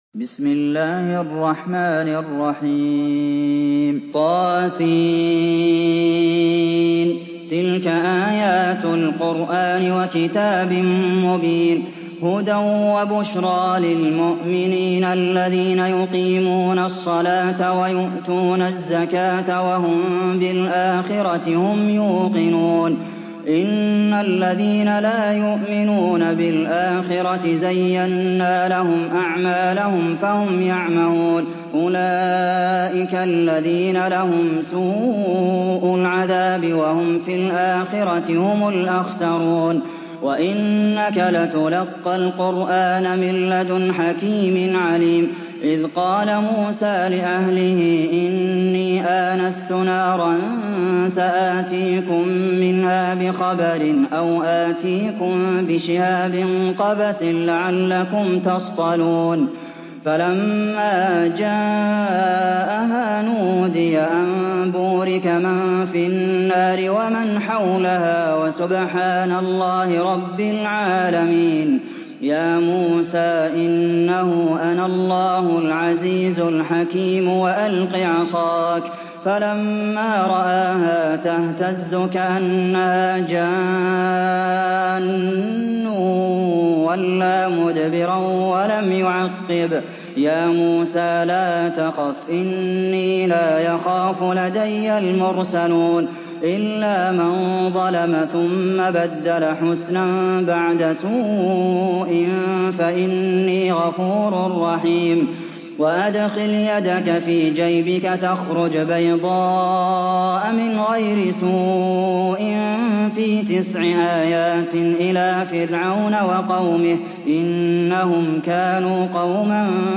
سورة النمل MP3 بصوت عبد المحسن القاسم برواية حفص عن عاصم، استمع وحمّل التلاوة كاملة بصيغة MP3 عبر روابط مباشرة وسريعة على الجوال، مع إمكانية التحميل بجودات متعددة.
مرتل